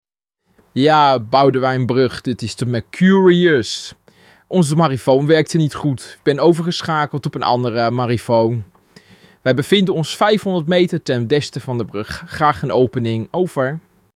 Verstoorde oproep